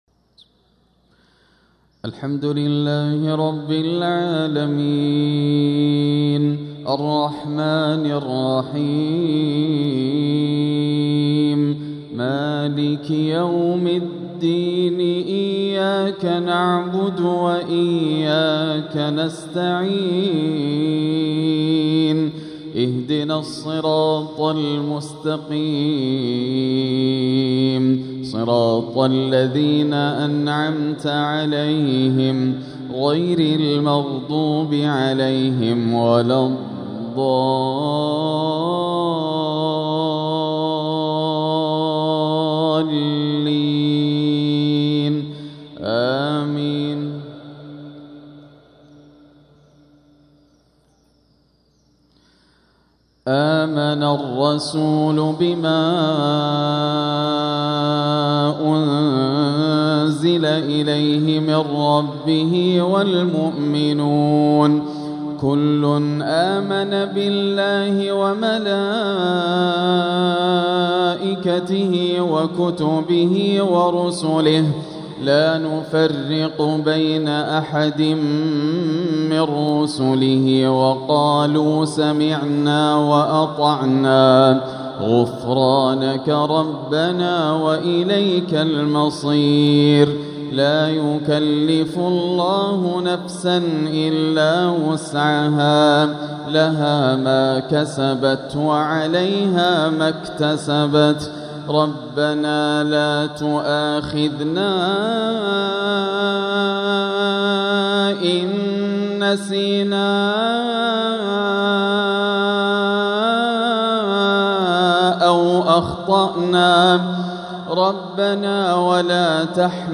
تلاوة راائعة لآخر سورة البقرة والإخلاص | مغرب الأربعاء 11 شوال 1446 > عام 1446 > الفروض - تلاوات ياسر الدوسري